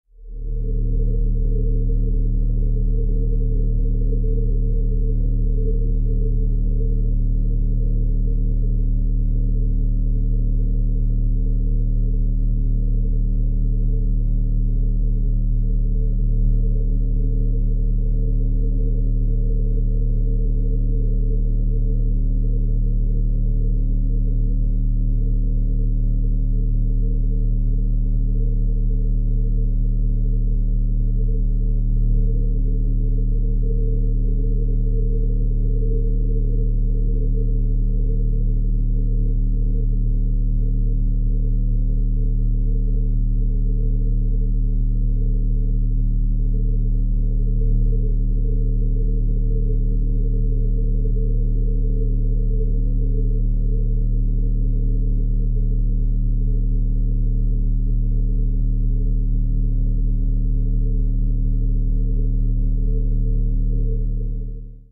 recharge.wav